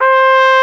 Index of /90_sSampleCDs/Roland LCDP12 Solo Brass/BRS_Trumpet 5-7/BRS_Tp 7 Warm